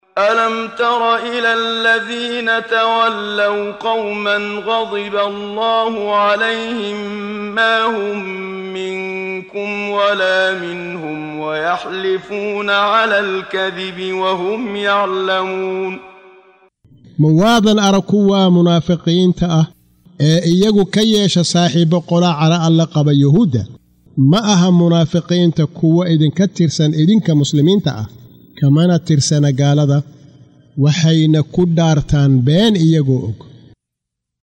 Waa Akhrin Codeed Af Soomaali ah ee Macaanida Suuradda Al-Mujaadilah ( doodeysa ) oo u kala Qaybsan Aayado ahaan ayna la Socoto Akhrinta Qaariga Sheekh Muxammad Siddiiq Al-Manshaawi.